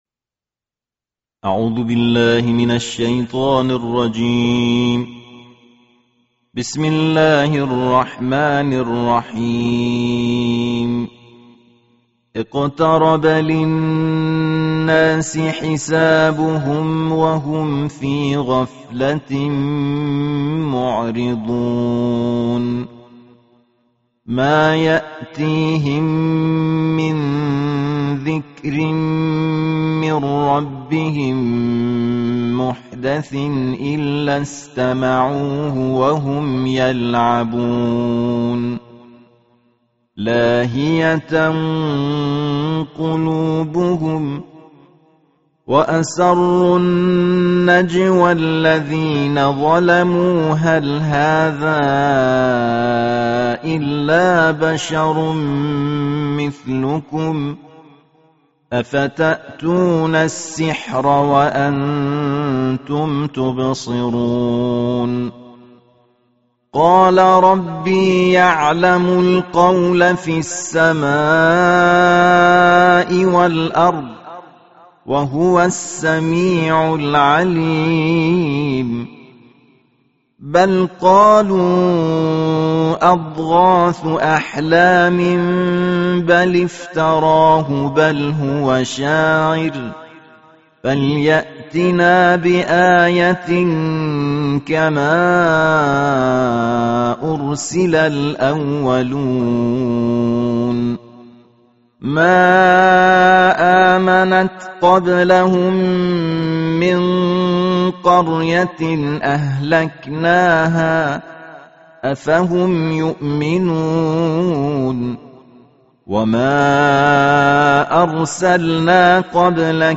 جزء هفدهم قرآن کریم با صدای قاری بین‌المللی کشورمان منتشر شده است. این قاری بین‌المللی در تلاوت خود سعی داشته تا در کنار اجرای مقامات اصیل عربی از نغمات فارسی و کشورهای همسایه جهت تلمیح الحان بهره‌مند شود.